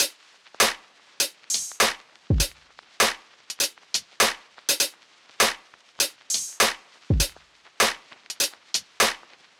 Unison Funk - 2 - 100bpm - Tops.wav